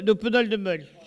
Locution